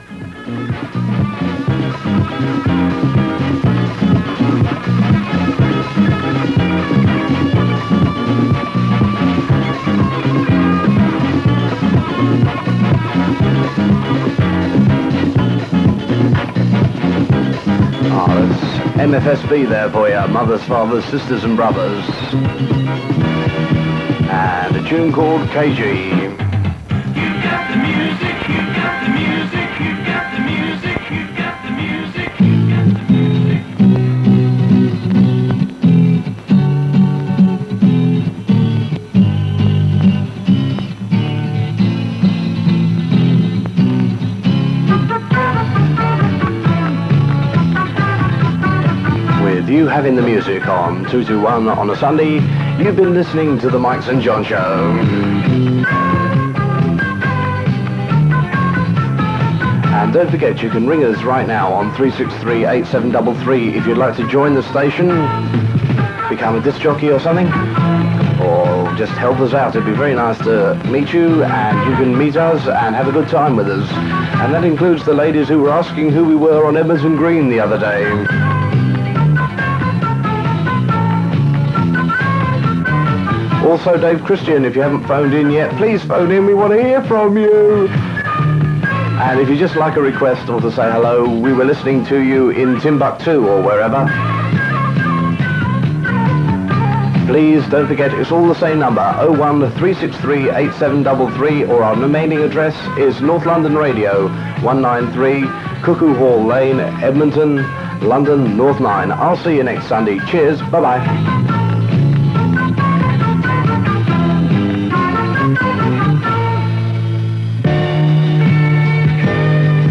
North London Radio were heard on Sunday's during 1978 and 1979 on 221m.
Interesting after sign off, there is some splatter from another station.